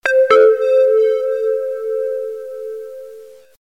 Классное стандартное смс от новой версии iPhone